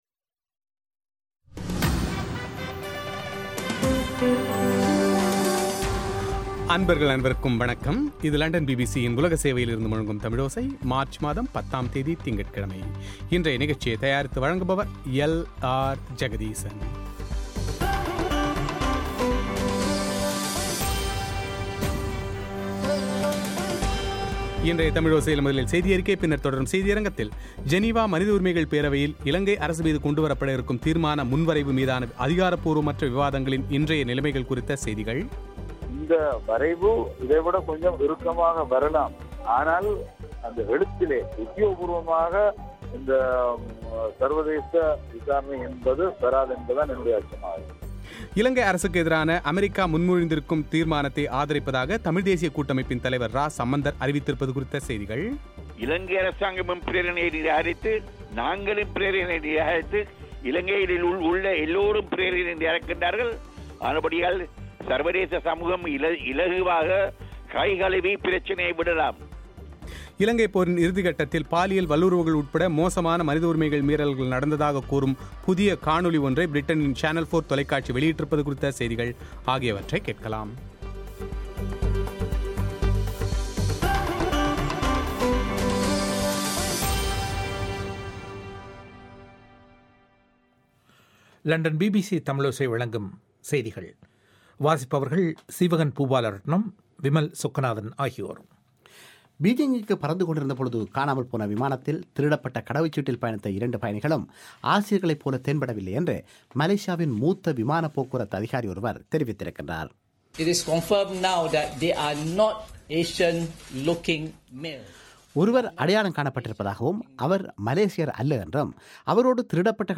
ஜெனீவா மனித உரிமைகள் பேரவையில் இலங்கை மீது கொண்டுவரப்படவிருக்கும் தீர்மான முன்வரைவு மீதான அதிகாரபூர்வமற்ற விவாதங்களின் இன்றைய நிலைமைகள் குறித்த செய்திகள்;